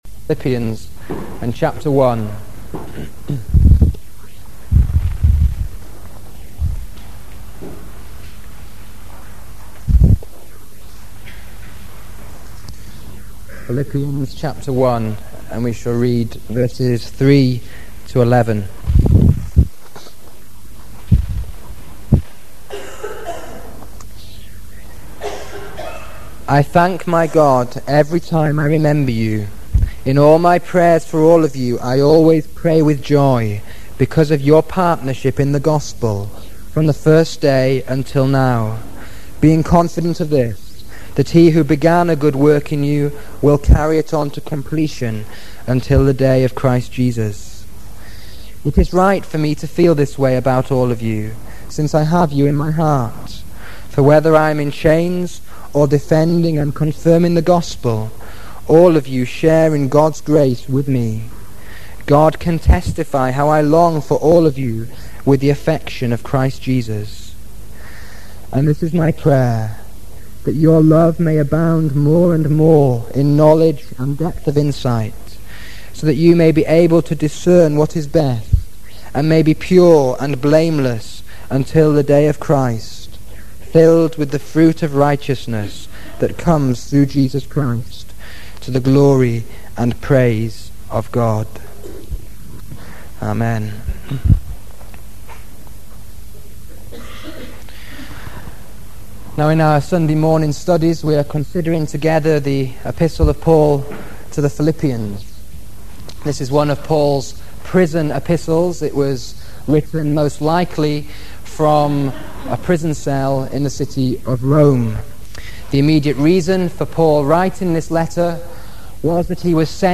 from tape